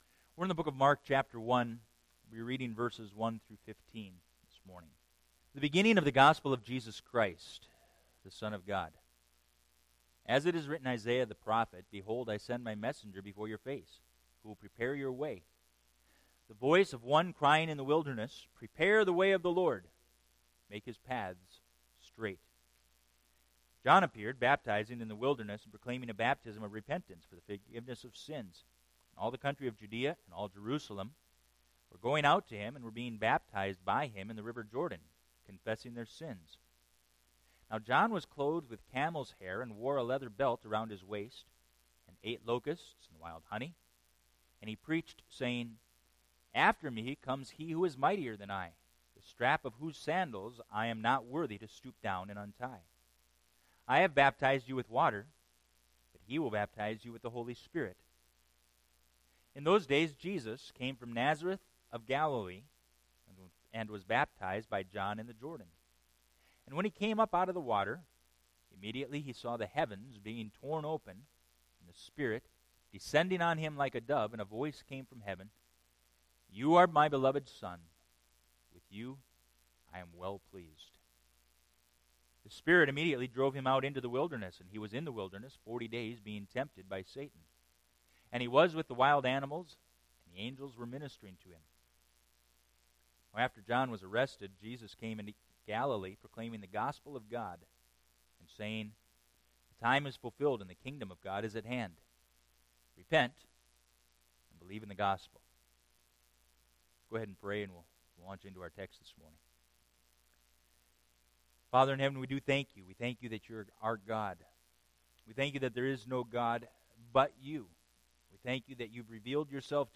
2018 Sermon - 07-08 - Valley View Bible Church